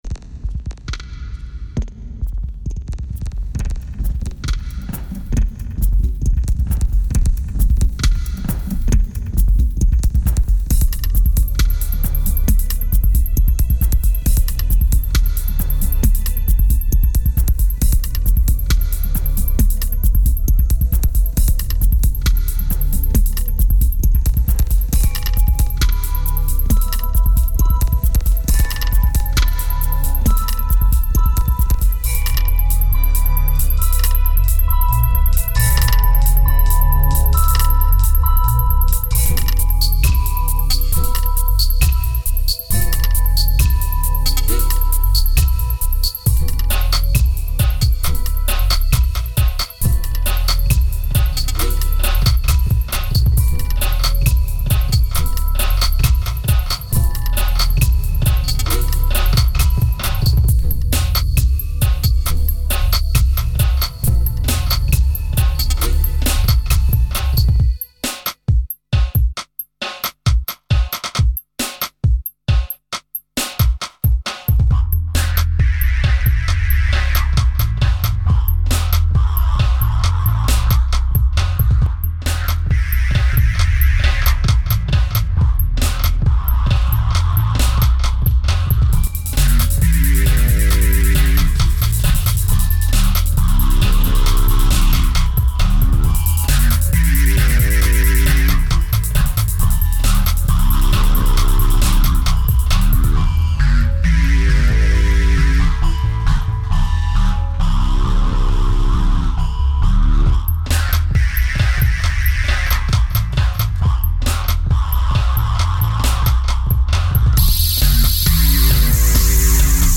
Tight and progressive, it seems to flow out more naturally.
2200📈 - -25%🤔 - 135BPM🔊 - 2008-11-02📅 - -280🌟